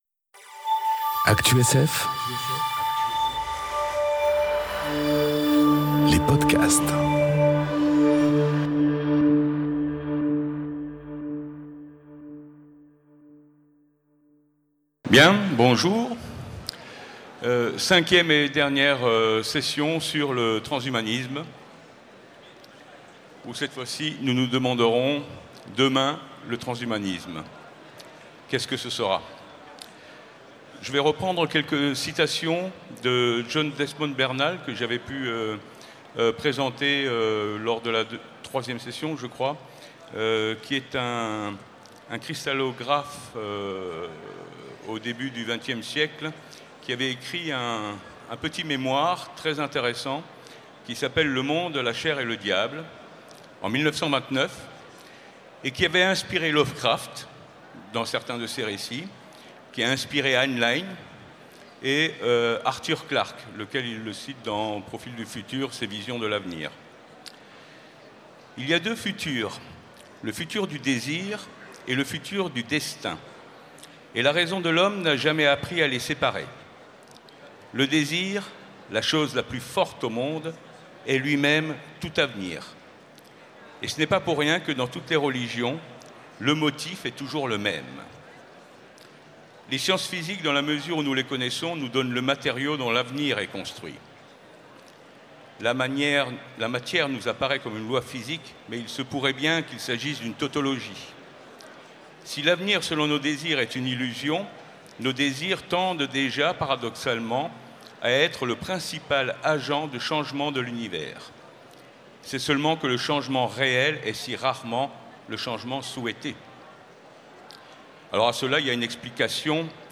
Conférence Transhumanisme 5 : Quel avenir pour le transhumanisme ? enregistrée aux Utopiales 2018